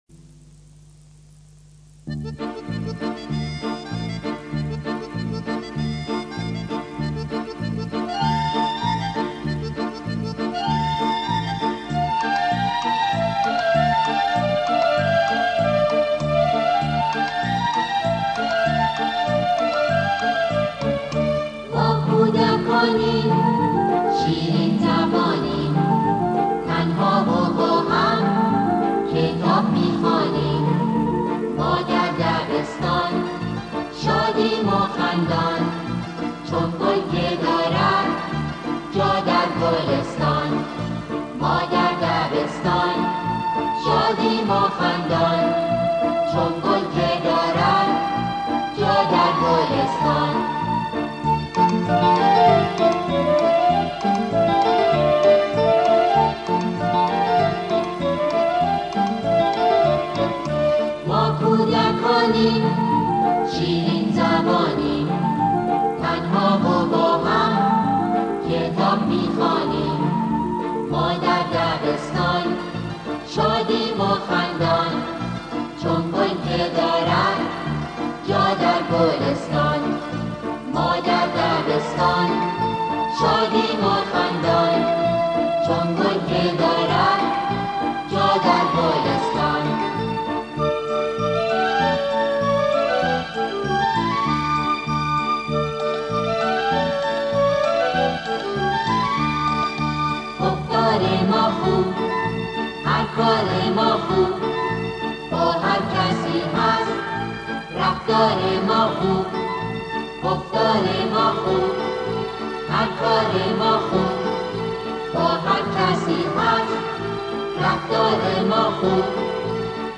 این اثر با اجرای گروه کر و تهیه شده در آموزش و پرورش است.